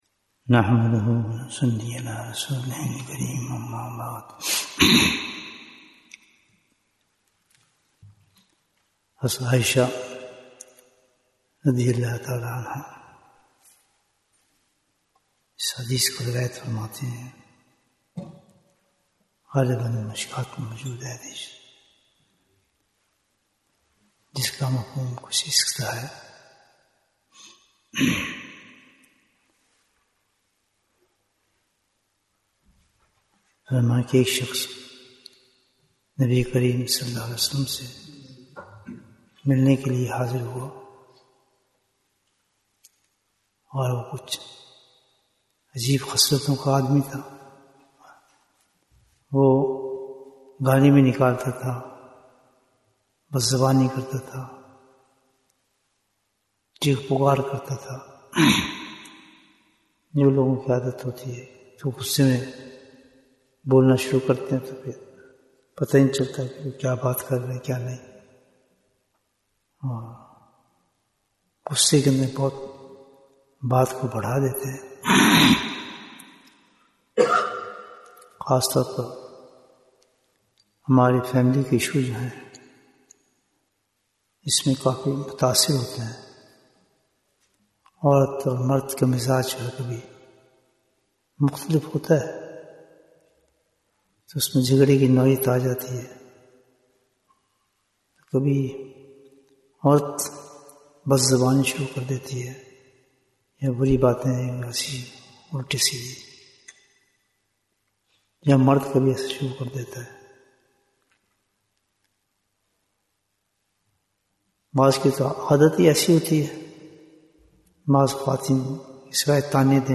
Bayan, 44 minutes 16th March, 2025 Click for English Download Audio Comments Jewels of Ramadhan 2025 - Episode 19 - What is the Message of Ramadhan?